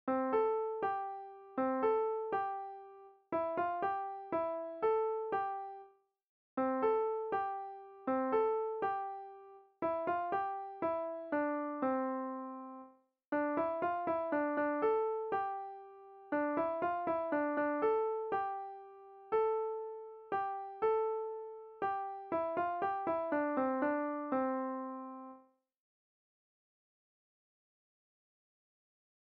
Nahrávky živých muzikantů si můžete poslechnout u písniček Vločka a Jinovatka, u ostatních si můžete poslechnout zatím jen melodie generované počítačem 🙂